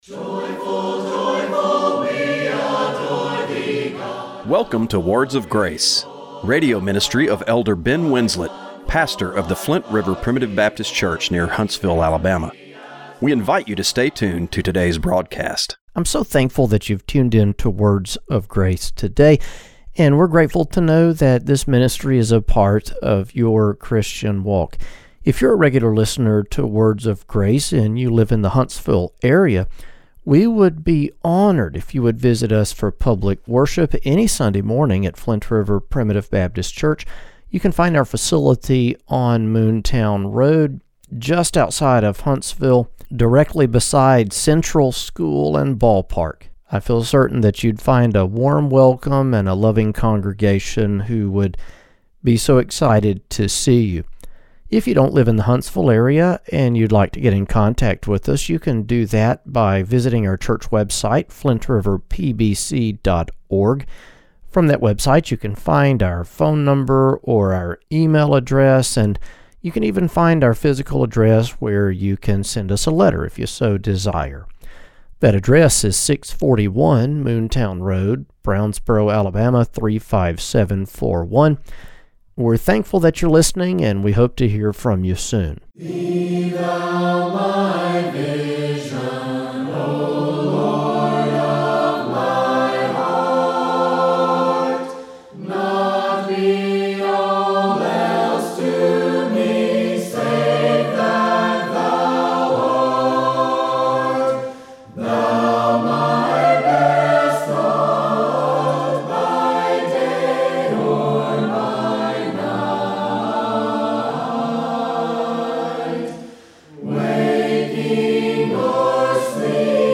Radio broadcast for February 15, 2026.